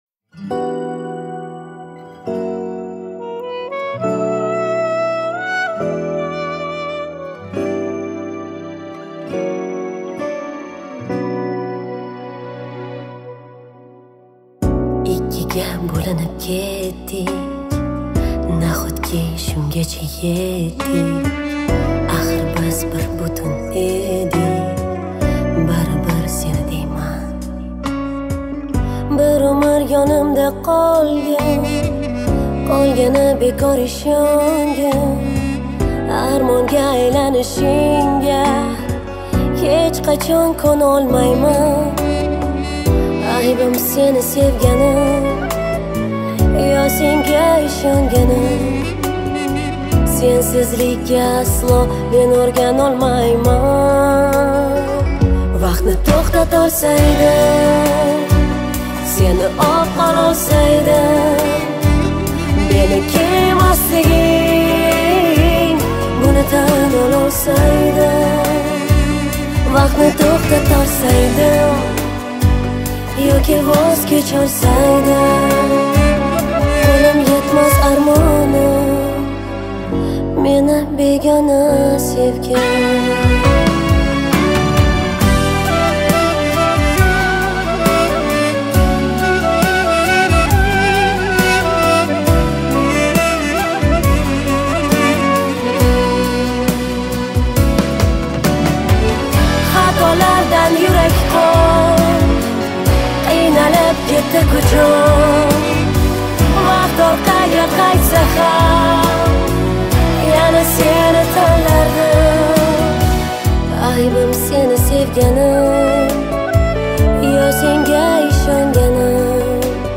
Скачать музыку / Музон / Узбекская музыка